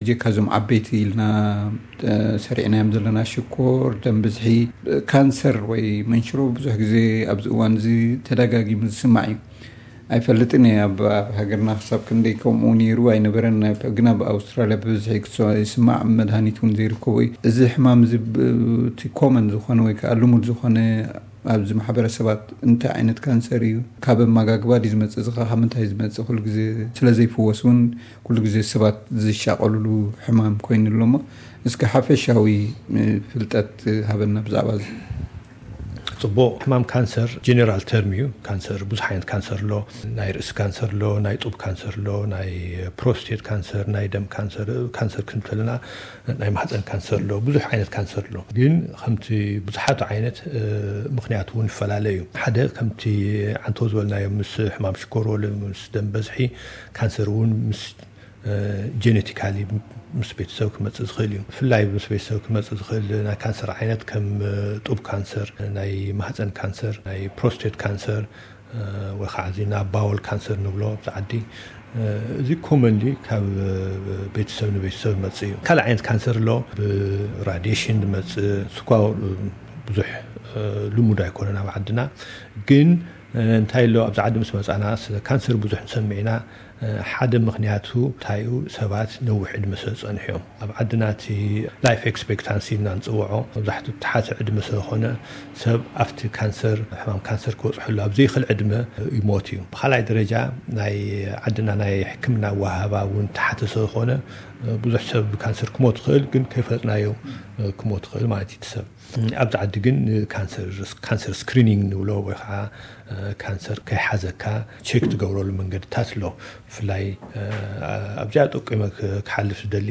ቃለ መሕትት